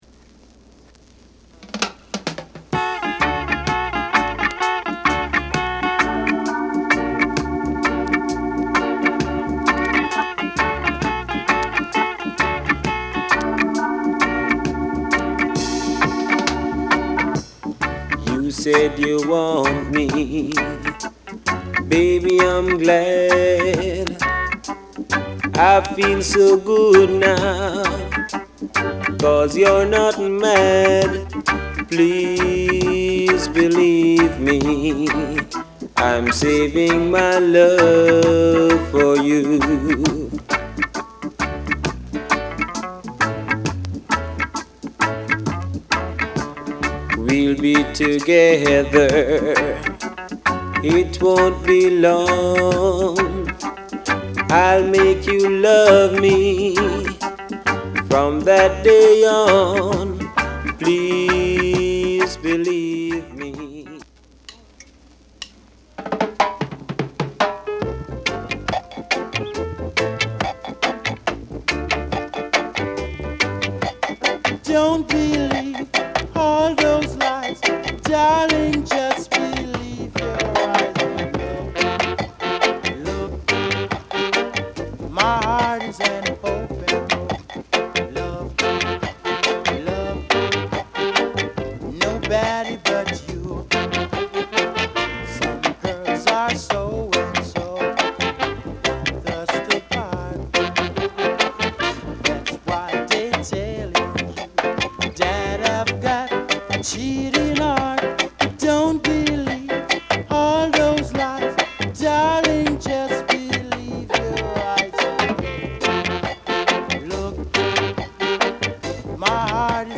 プレス・ノイズ有り（JA盤、Reggaeのプロダクション特性とご理解お願い致します）。